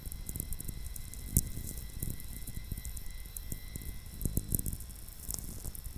Cumiana, NW Italy